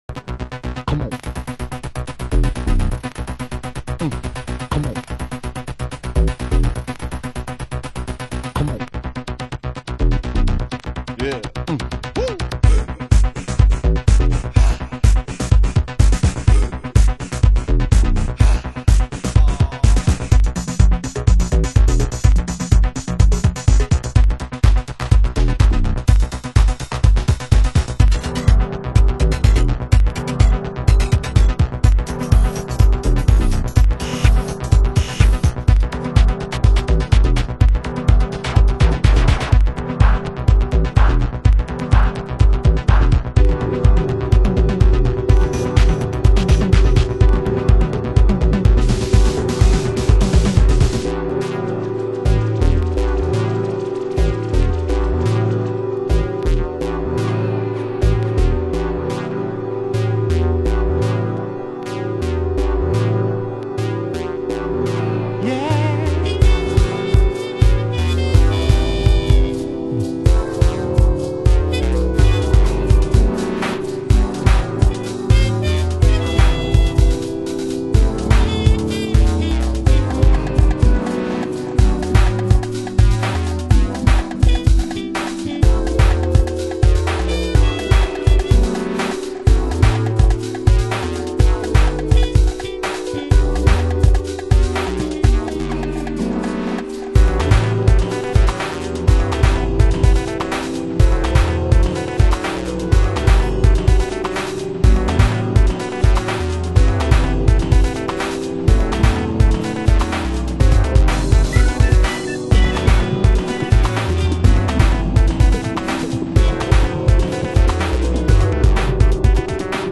盤質：少しチリパチノイズ有　　　ジャケ：薄汚れ有